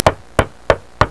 snd_1643_knock.wav